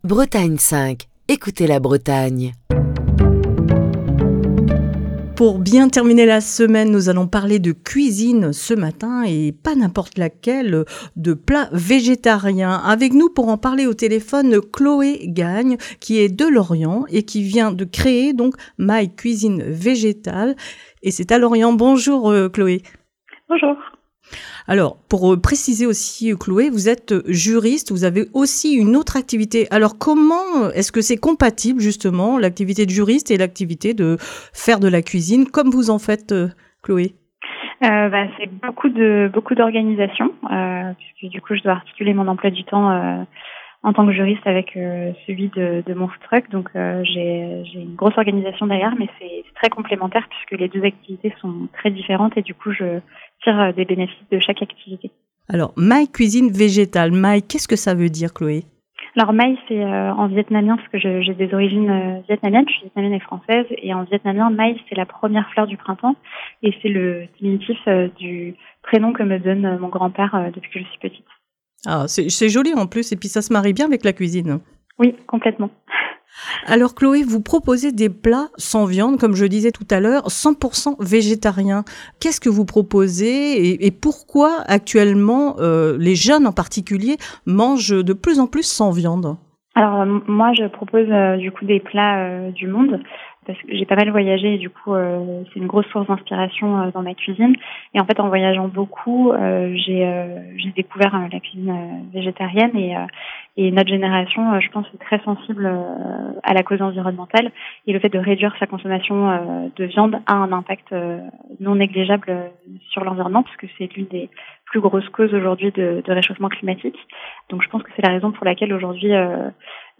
Pour bien finir la semaine, nous allons parler cuisine dans le Coup de fil du matin.